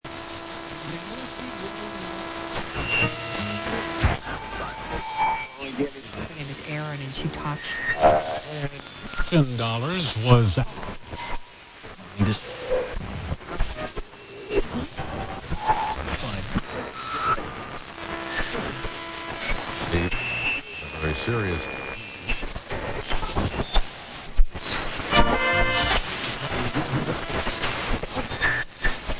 am-band-static-6036.mp3